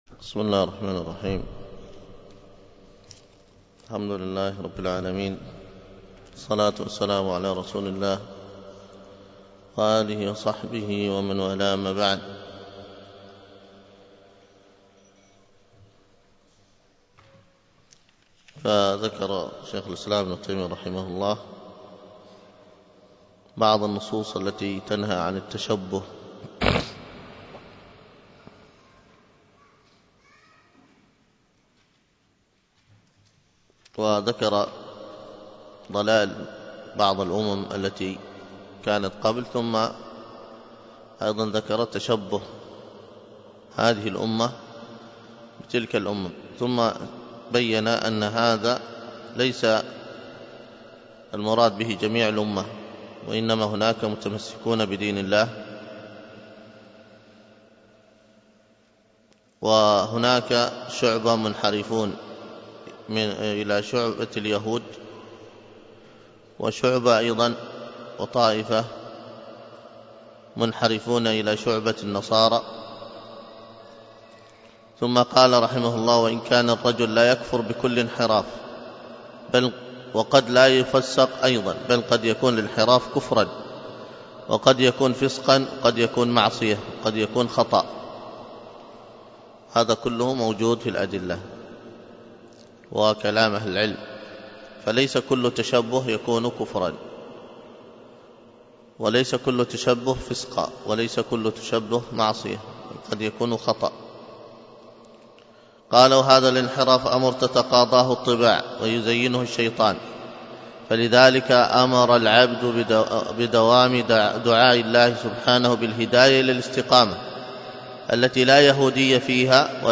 الدروس العقيدة ومباحثها